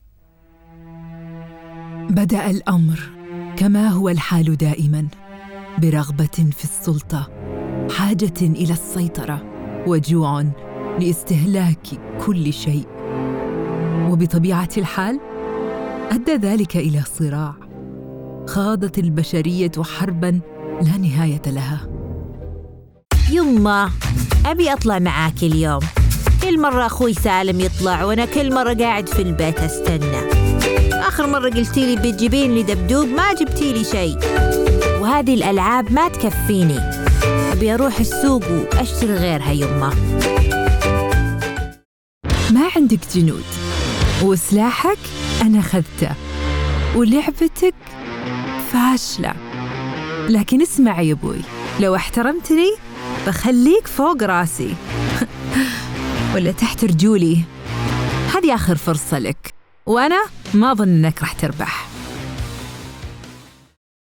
Profundo, Natural, Llamativo